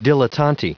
Prononciation du mot dilettanti en anglais (fichier audio)
Prononciation du mot : dilettanti